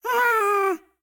Minecraft Version Minecraft Version snapshot Latest Release | Latest Snapshot snapshot / assets / minecraft / sounds / mob / happy_ghast / ambient1.ogg Compare With Compare With Latest Release | Latest Snapshot